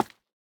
Minecraft Version Minecraft Version latest Latest Release | Latest Snapshot latest / assets / minecraft / sounds / block / candle / break3.ogg Compare With Compare With Latest Release | Latest Snapshot